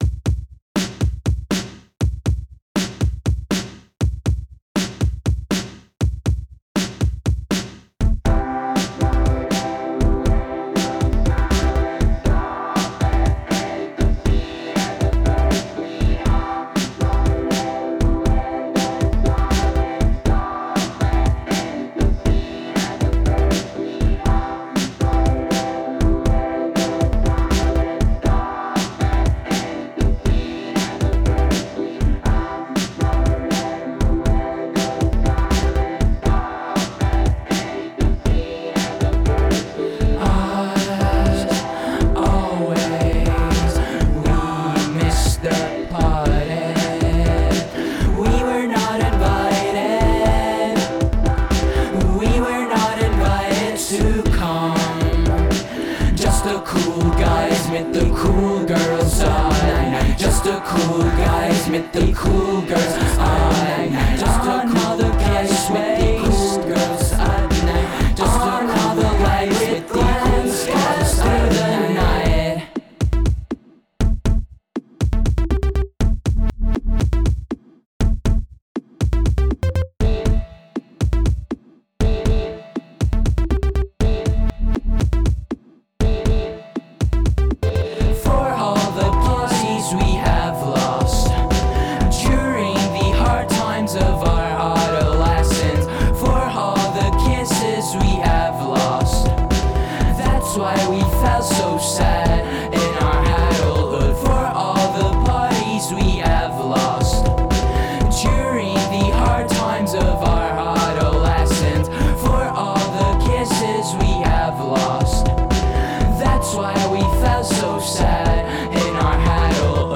(demo)